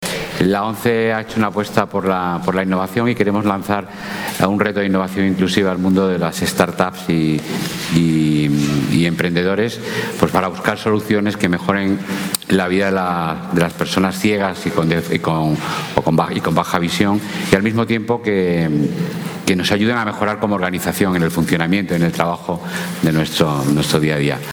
explicó formato MP3 audio(0,47 MB) en el acto de presentación.